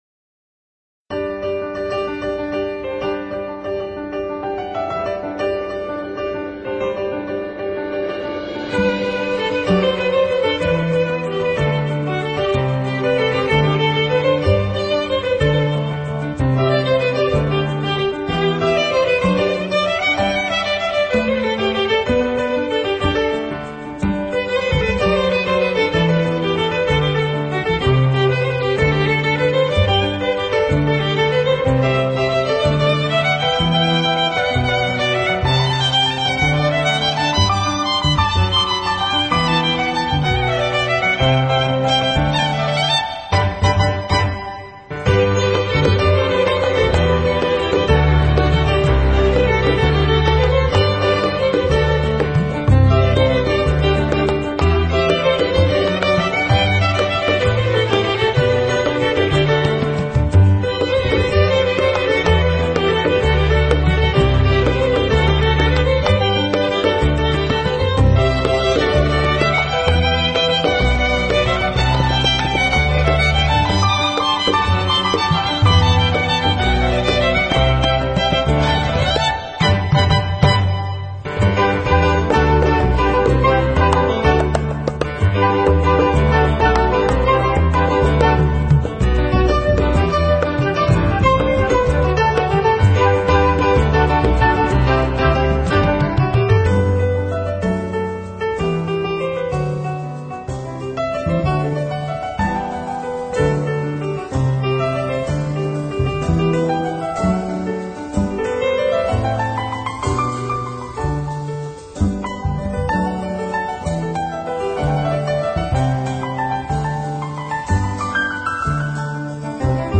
是靠手指灵巧的长笛和小提琴（无意识的拨弄）合作完成的。